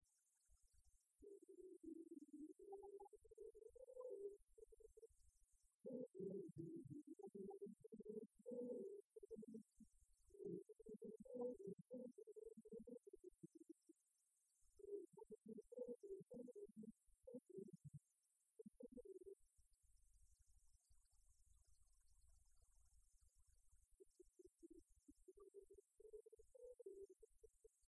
Club des retraités de Beaupréau association
Concert de la chorale des retraités
Pièce musicale inédite